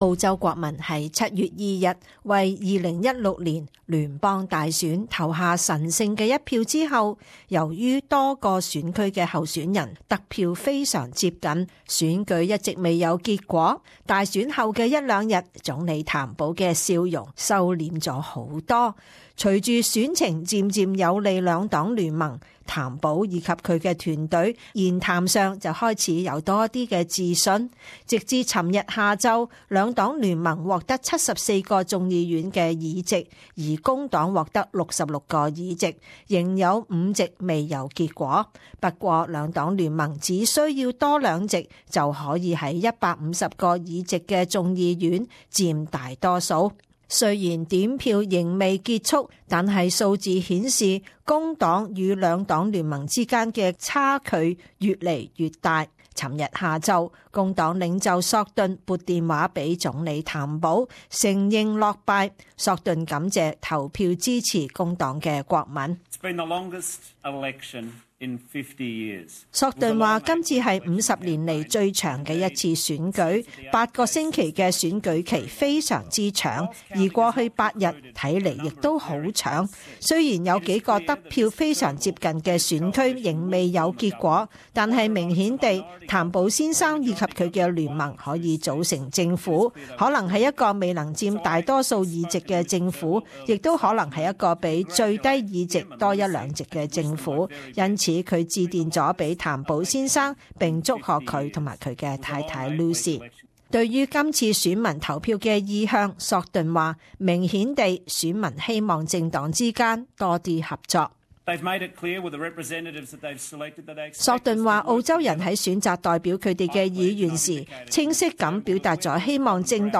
Prime Minister Malcolm Turnbull during a press conference at Commonwealth Parliamentary Offices, in Sydney Source: AAP